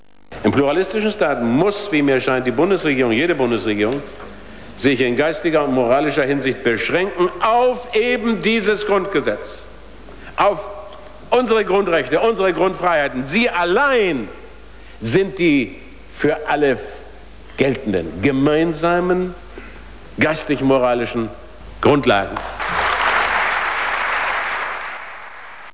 Möchten Sie Helmut Schmidt einmal im Interner Link O-Ton hören?